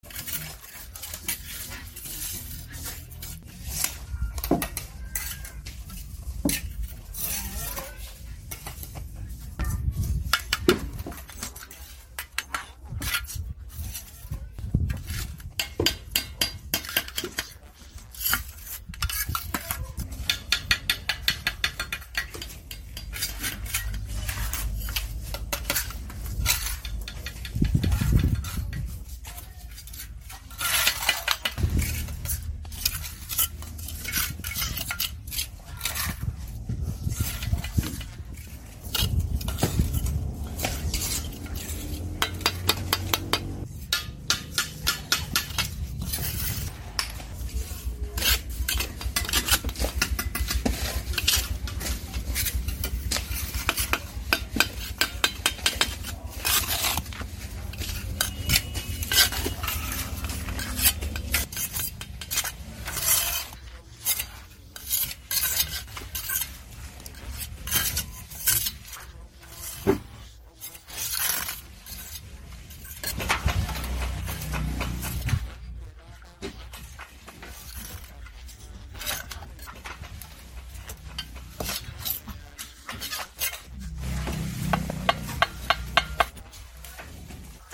Tiếng dùng Bay, Bê, trát vữa… của thợ xây, thợ hồ
Thể loại: Tiếng động
Description: Tiếng dùng bay, bê, trát vữa của thợ xây, tiếng phết, quét, cạo, xoa, trộn, vữa, xi măng, âm thanh xây dựng, thợ hồ vang lên sột soạt, đều đặn, hòa quyện giữa âm thanh kim loại chạm vữa và nhịp điệu lao động. Những âm thanh mộc mạc như cạo, xoa, trộn vữa, hay tiếng bay lướt trên bề mặt xi măng tạo hiệu ứng chân thực, sống động, lý tưởng cho chỉnh sửa video, tái hiện cảnh công trường xây dựng.
tieng-dung-bay-be-trat-vua-cua-tho-xay-tho-ho-www_tiengdong_com.mp3